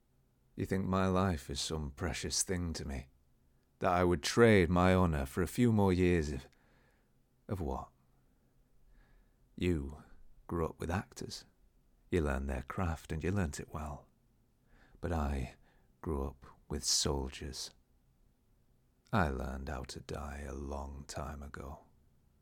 Northern accent (Sheffield) Scottish accent (Edinburgh) Spanish accent (Malaga)
Northern-Sheffield-Accent-Sample.mp3